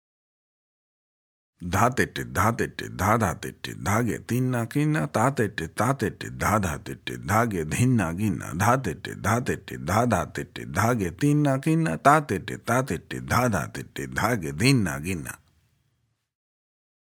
Spoken – Medium